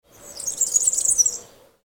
Bird Call in Flight – Short Nature Sound Effect
Single, clear vocalization during flight. Isolated nature sound ideal for cinematic sound design, apps, or wildlife projects requiring a quick, authentic bird call. Spring ambience.
Bird-call-in-flight-short-nature-sound-effect.mp3